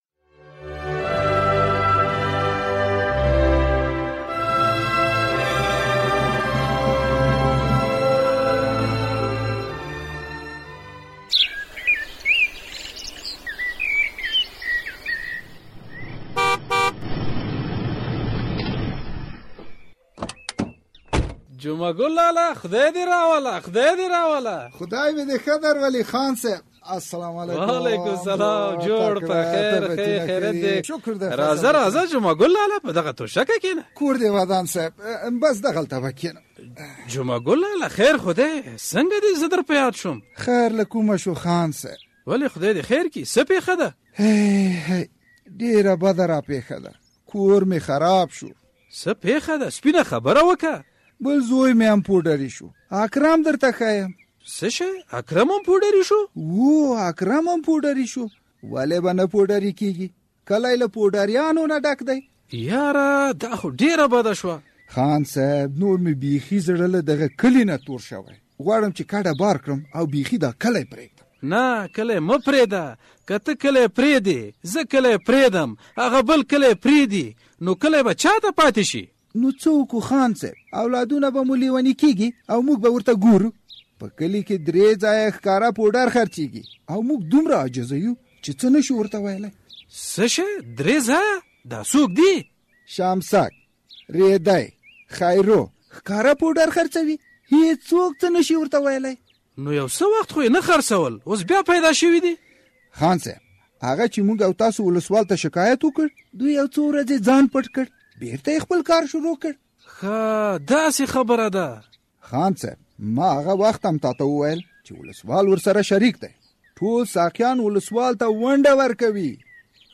دا به په ډرامه کې واورو چې عبدالنافع همت ليکلې ده.